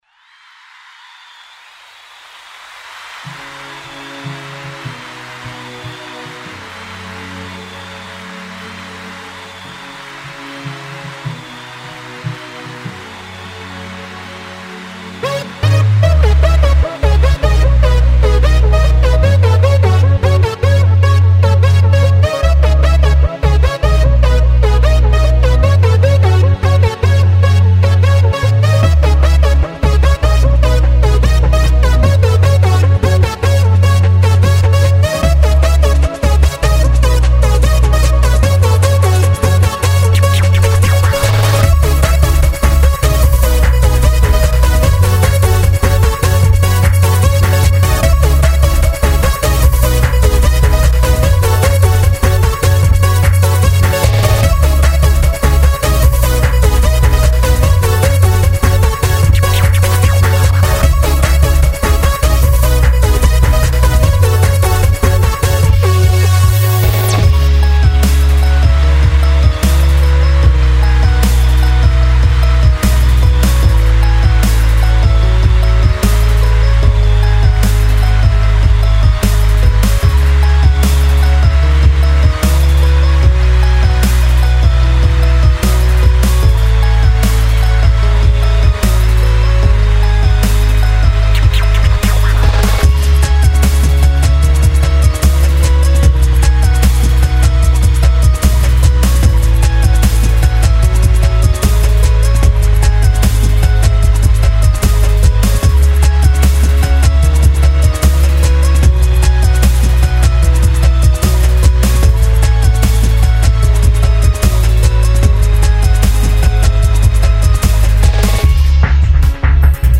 Tags: Rock Songs Musik